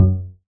bassattack.ogg